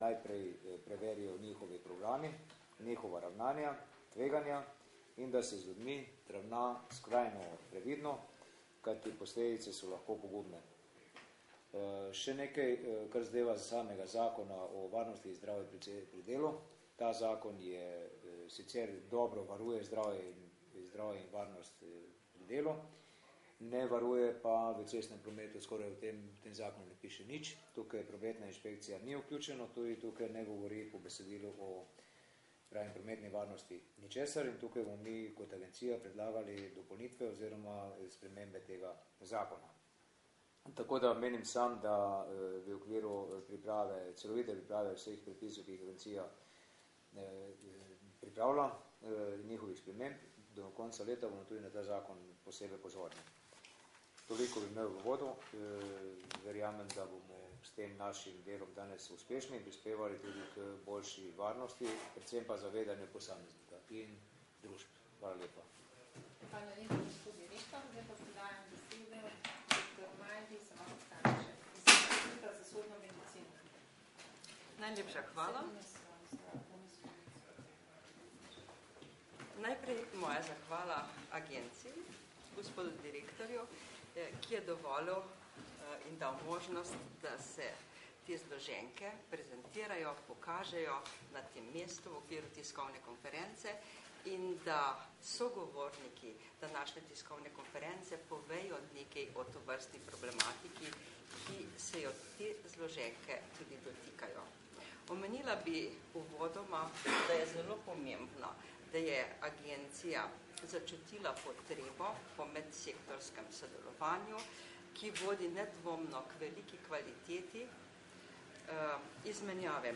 Informacija z novinarske konference o utrujenosti in zaspanosti za volanom
5. 6. 2013Javna agencija RS za varnost prometa je 5. junija 2013 organizirala novinarsko konferenco, s katero je opozorila na problem utrujenosti in zaspanosti za volanom ter vožnje pod vplivom zdravil in drugih psihoaktivnih snovi.